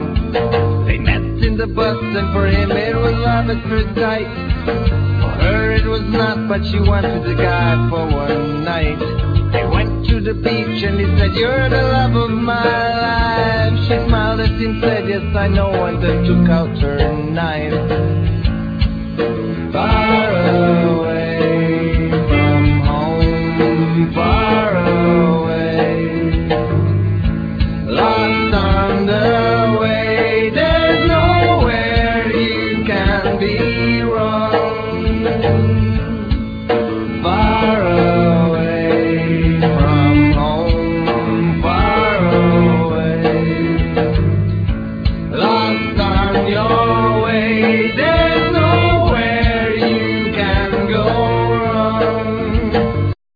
Vocals,Violin,Guitar,Bass
Guitars,Bass,Vocals
Cello,Vocals
Drums,Percussions